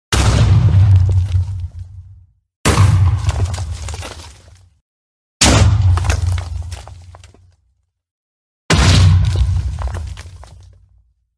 Звуки взрыва динамита
На этой странице собраны реалистичные звуки взрыва динамита — от резких хлопков до мощных детонаций.
В коллекции как одиночные взрывы, так и серии с эхом, обвалами и другими деталями.